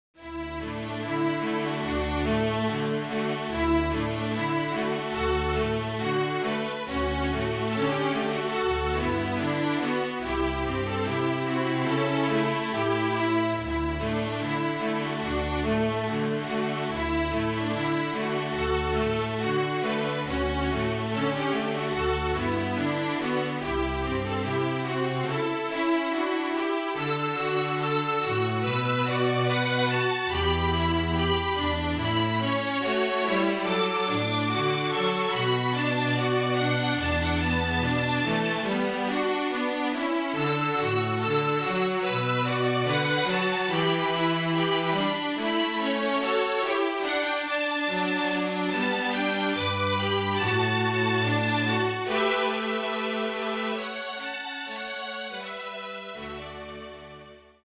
BROADWAY HITS
FLUTE TRIO
Flute, Violin and Cello (or Two Violins and Cello)
MIDI